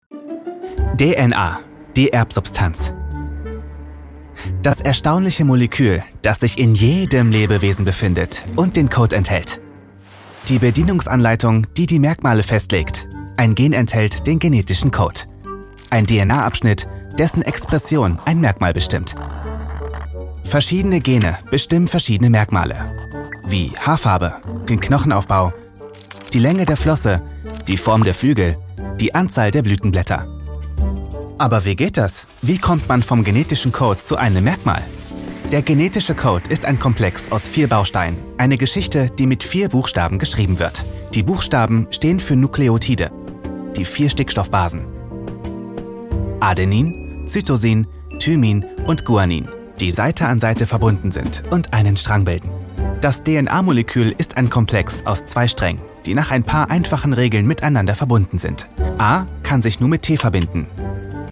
Male
Approachable, Assured, Authoritative, Bright, Bubbly, Character, Confident, Conversational, Cool, Corporate, Energetic, Engaging, Friendly, Funny, Gravitas, Natural, Posh, Reassuring, Sarcastic, Smooth, Soft, Streetwise, Upbeat, Versatile, Wacky, Warm, Witty, Young
Voice reels
Audio equipment: Soundproof recording booth — 38 dB in the midrange and up to 84 dB in the high-frequency range Microphone: Neumann TLM 103 dbx 286s Microphone Preamp & Channel Strip Processor, SessionLink PRO Conferencing wave plugins